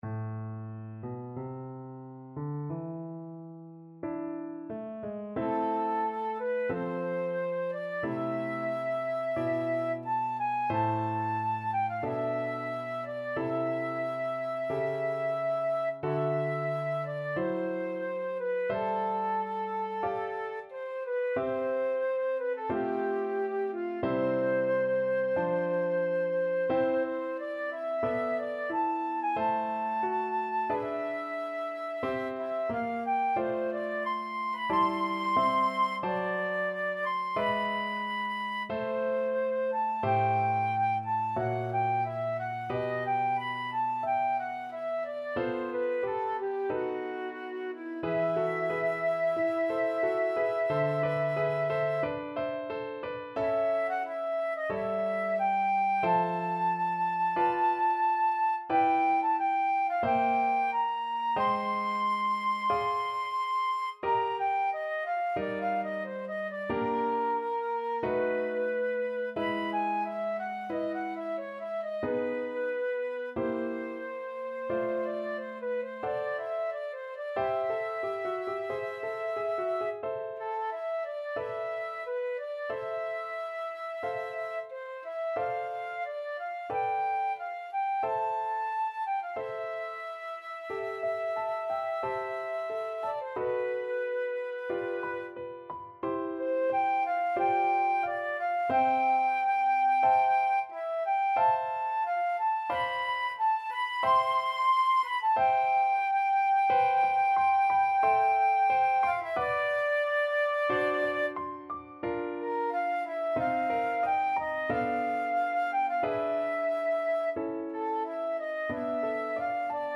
His music is characterized by unusual harmonies and modulations
A minor (Sounding Pitch) (View more A minor Music for Flute )
4/4 (View more 4/4 Music)
Adagio, molto tranquillo (=60) =45
Flute  (View more Intermediate Flute Music)